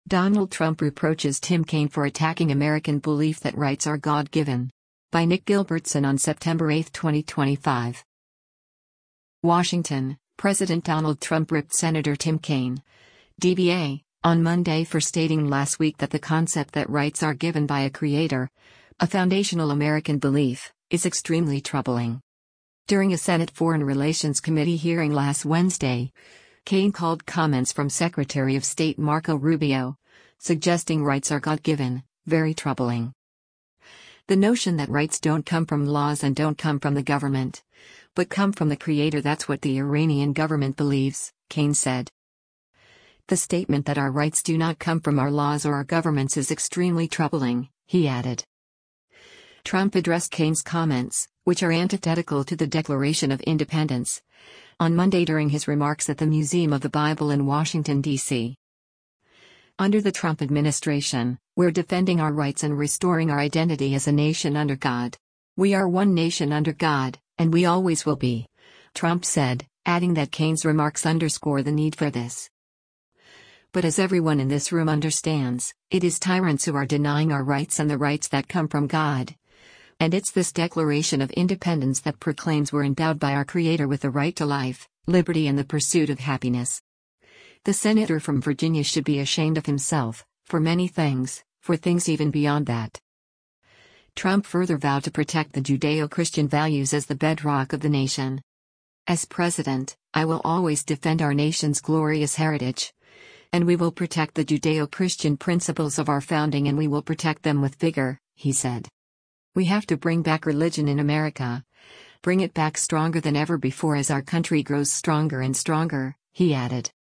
Trump addressed Kaine’s comments, which are antithetical to the Declaration of Independence, on Monday during his remarks at the Museum of the Bible in Washington, DC.